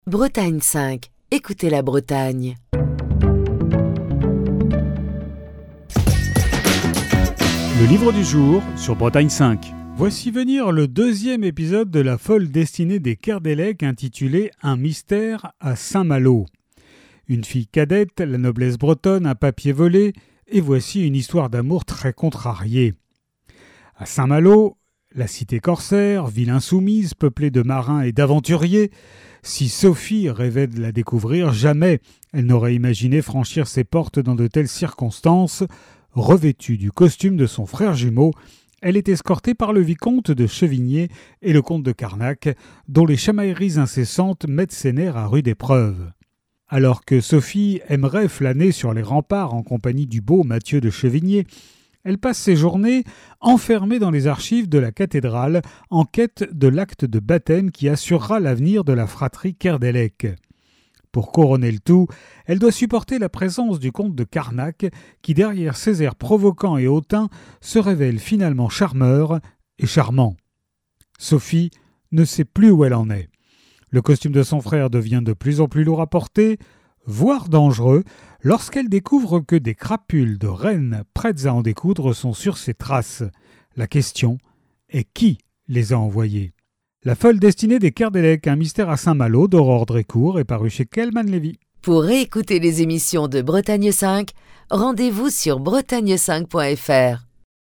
Fil d'Ariane Accueil Les podcasts La Folle Destinée des Kerdelec - Aurore Drécourt La Folle Destinée des Kerdelec - Aurore Drécourt Chronique du 23 mai 2024.